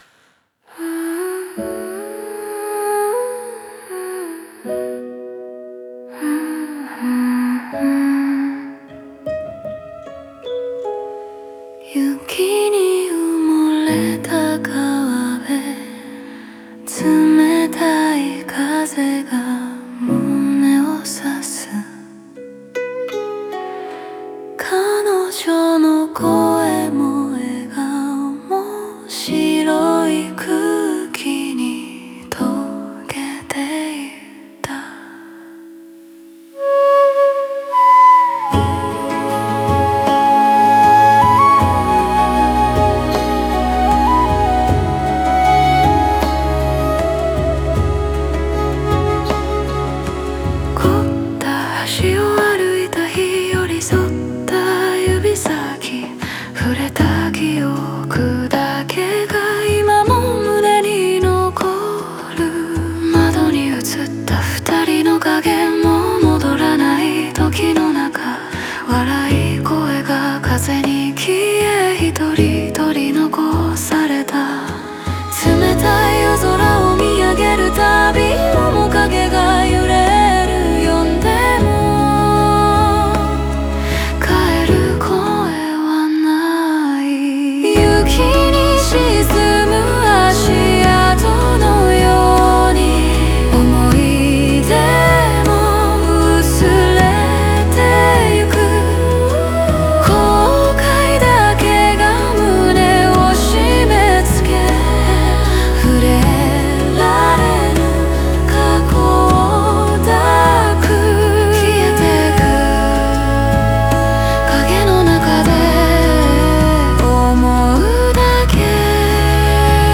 オリジナル曲♪